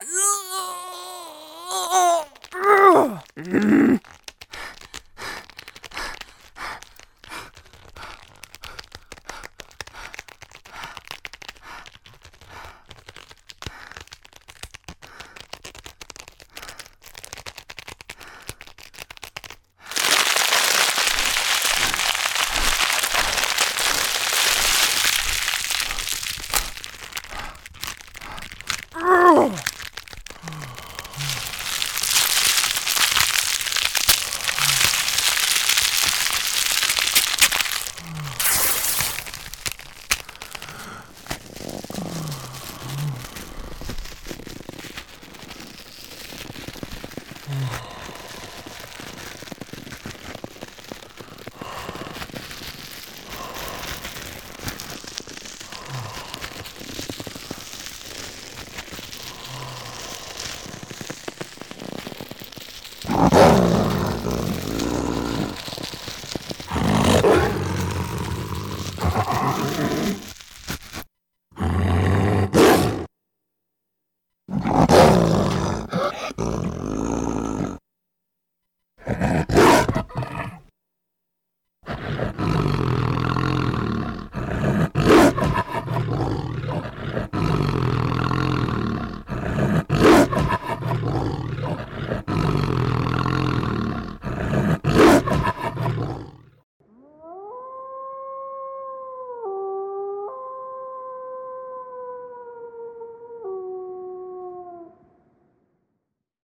Female Werewolf Transformation Audio
just a random girl transforming into a werewolf grunting and relaxing as the beast takes over with a big howl
howling grunting beast wolf